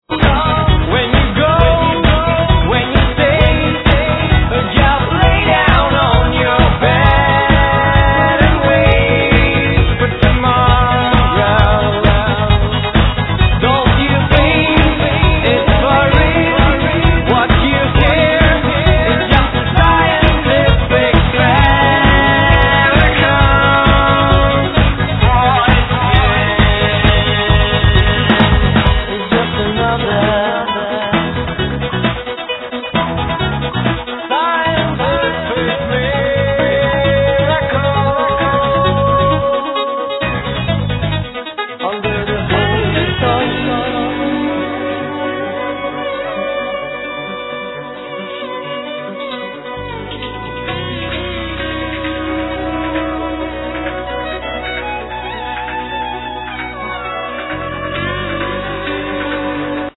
Vocals, Guitar
Bass
Guitar, Guitar synth
Keyboards
Violin
Percussions